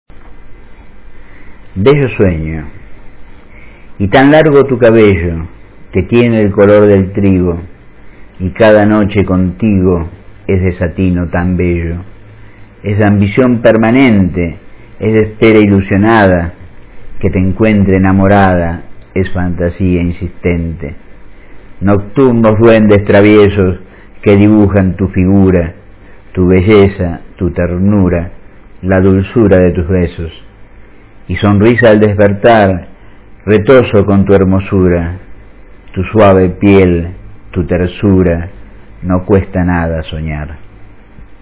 Recitado por el autor (0:41", 161 KB)